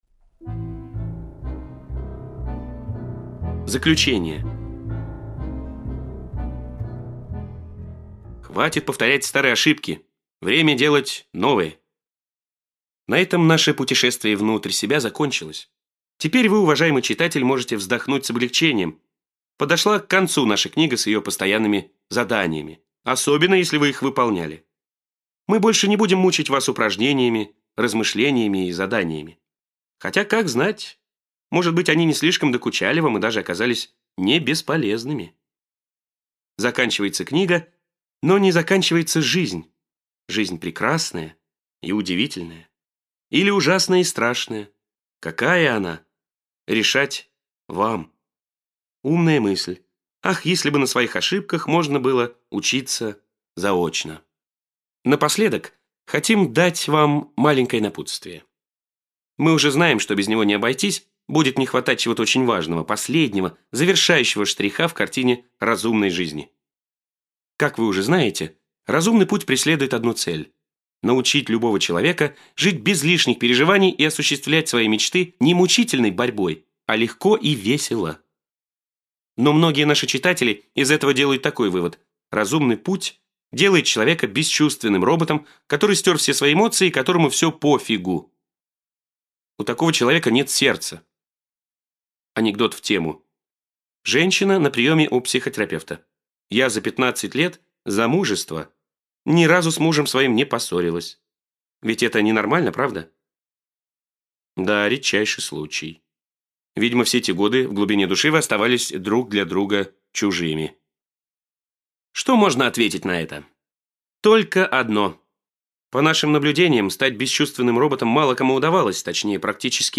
Аудиокнига Улыбнись, пока не поздно! Советы брачующимся, забракованным и страстно желающих забраковаться | Библиотека аудиокниг